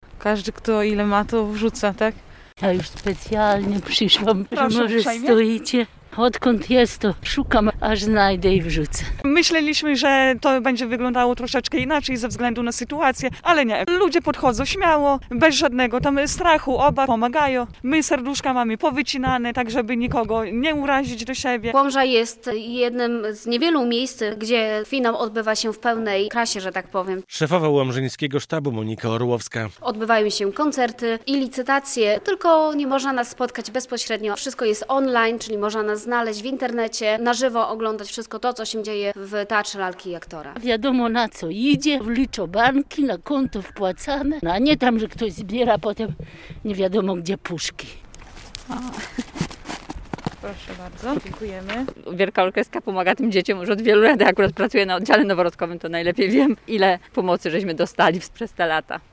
29. Finał WOŚP w Łomży - relacja